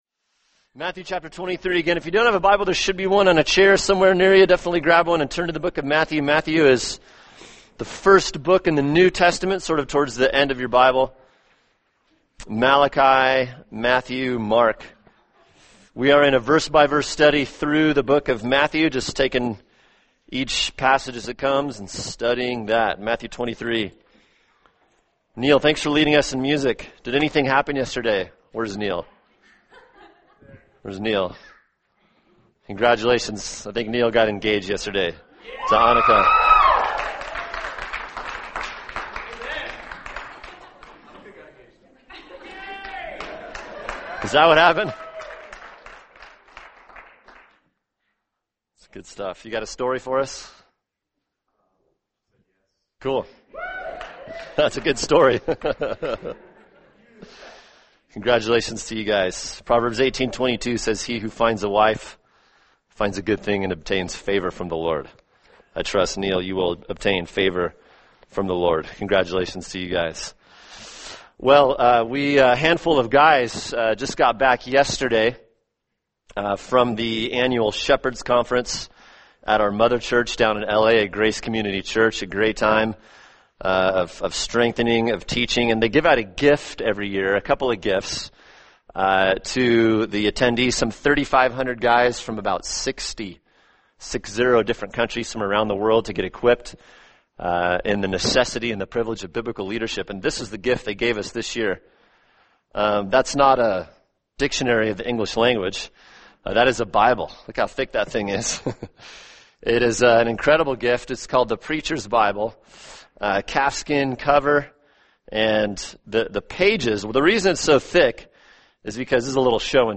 [sermon] Matthew 23:1-12 – God’s Concern for Qualified Spiritual Leadership | Cornerstone Church - Jackson Hole